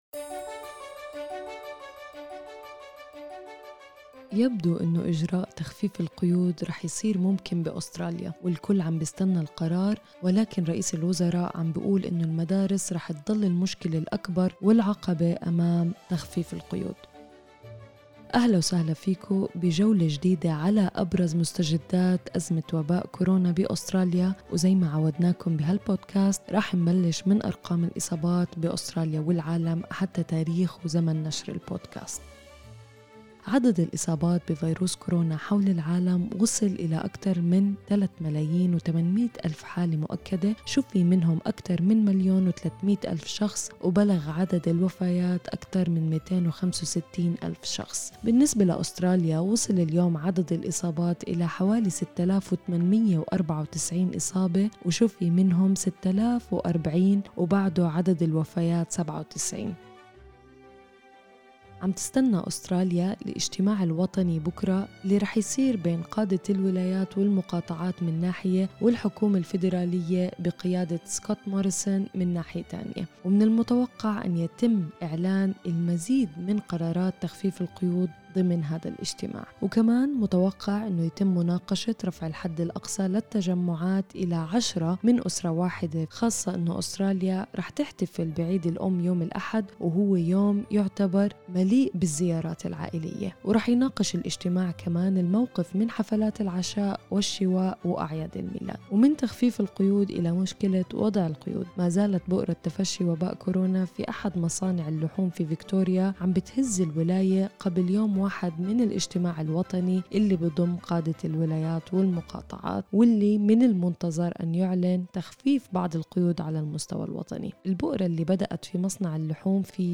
أخبار الكورونا اليوم 7/5/2020